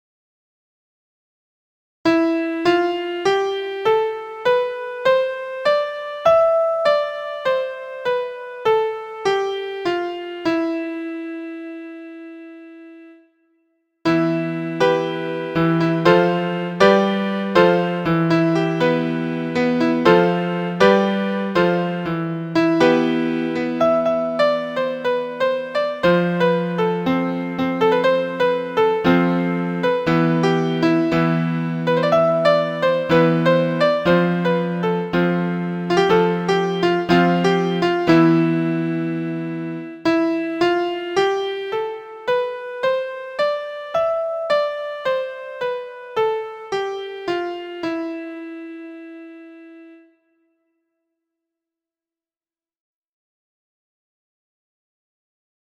PHRYGIAN
The half step between scale degrees one and two gives it a distinctive sound.
Phrygian.mp3